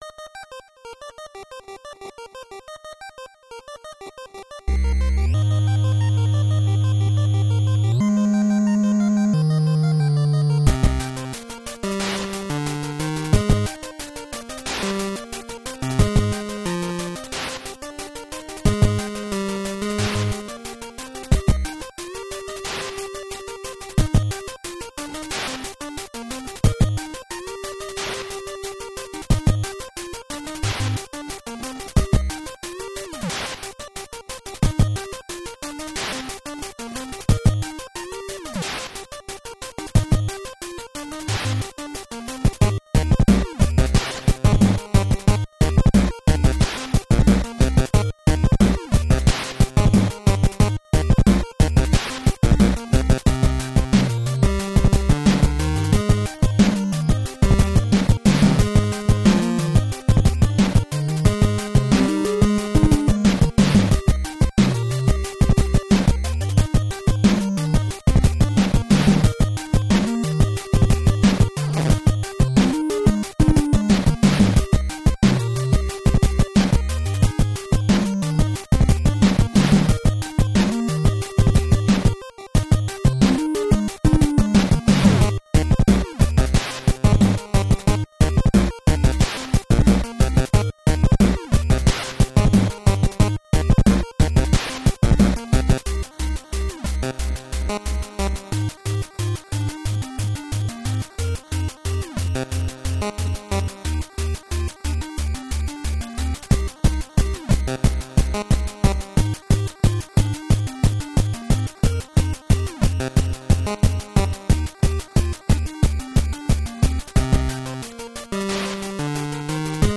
chiptune